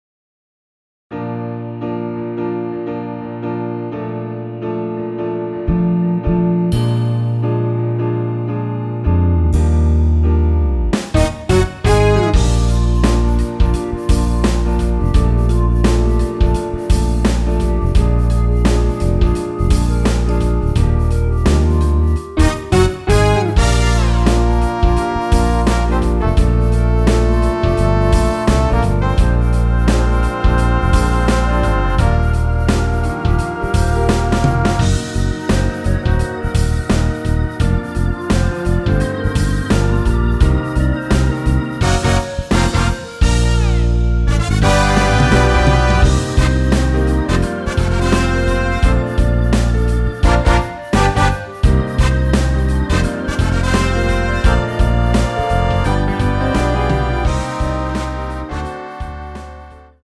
원키에서(-3)내린 멜로디 포함된 MR입니다.
Bb
앞부분30초, 뒷부분30초씩 편집해서 올려 드리고 있습니다.
중간에 음이 끈어지고 다시 나오는 이유는